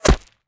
bullet_impact_dirt_01.wav